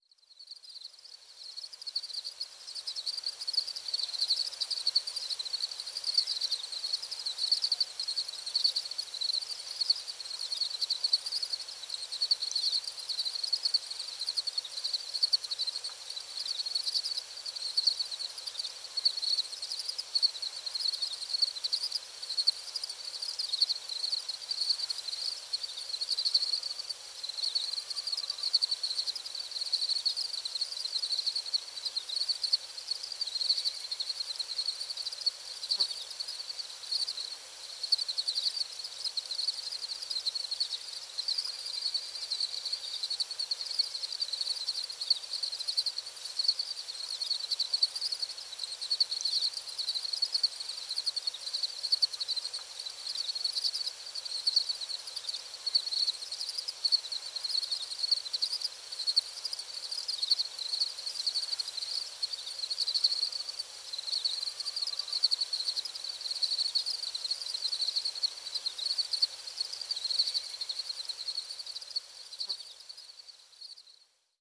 Two recordings from a late-summer visit to Fillongley Provincial Park.
2. Crickets (and faintly in the background, Killdeer)
This time, I recorded some of the sounds down at the beach as the creek was pretty dry after the long, dry, and hot summer.
Crickets-Edited.wav